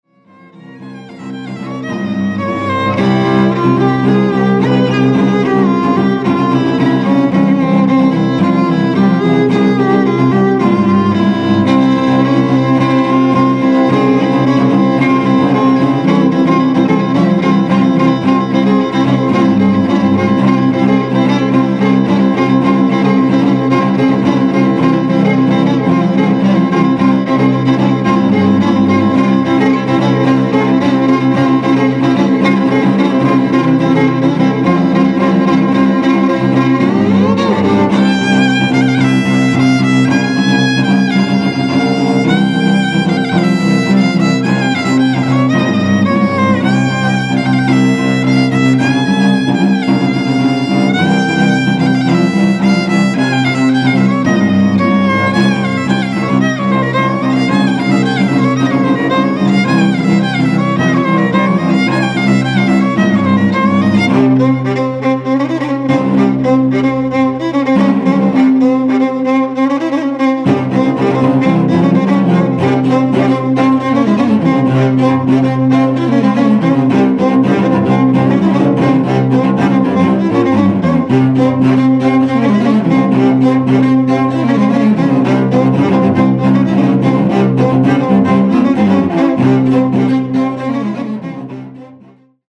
playing the violin
playing the cello.
elements of Oriental music, psychedelic rock and free jazz
improvisations on previously agreed on themes.